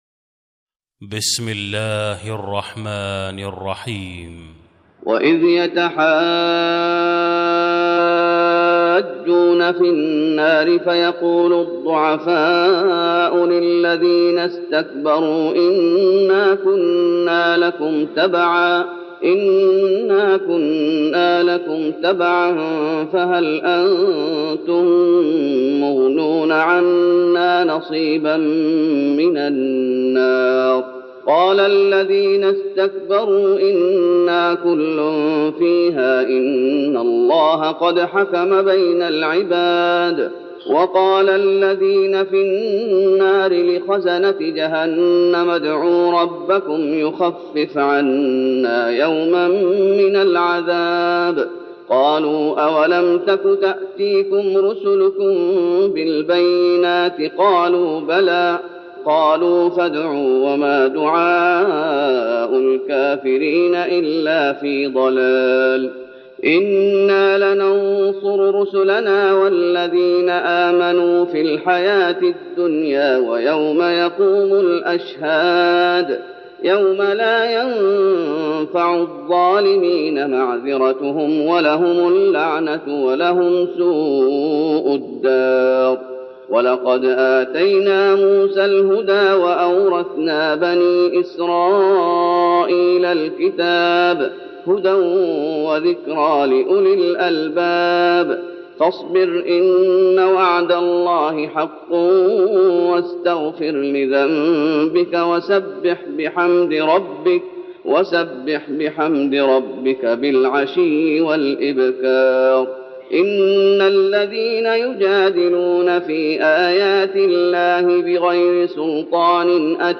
تراويح رمضان 1412هـ من سورة غافر (47-85) Taraweeh Ramadan 1412H from Surah Ghaafir > تراويح الشيخ محمد أيوب بالنبوي 1412 🕌 > التراويح - تلاوات الحرمين